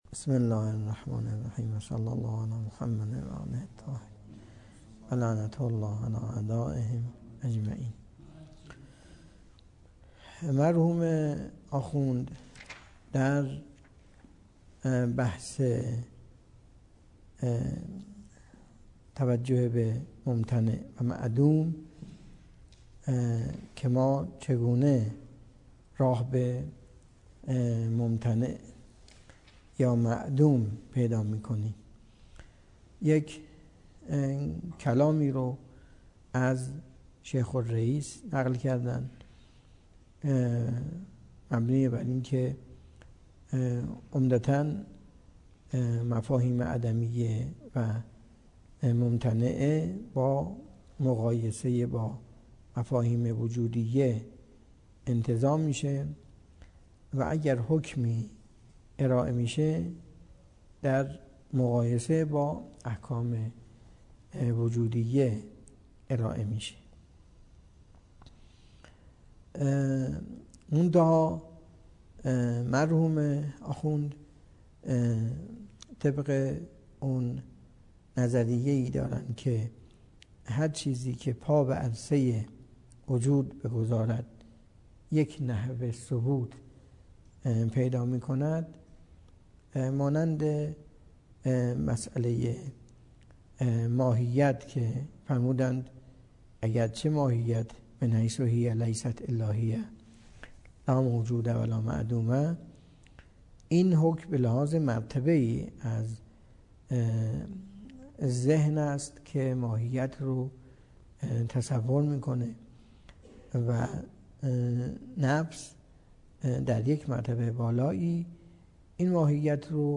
درس فلسفه اسفار اربعه
سخنرانی